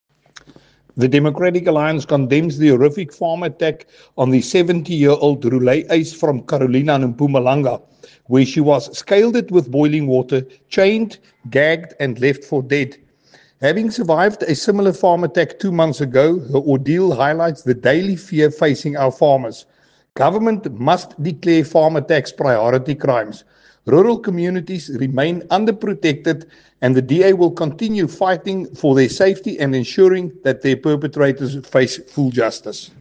Issued by Willie Aucamp MP – DA Spokesperson on Agriculture
Afrikaans soundbites by Willie Aucamp MP.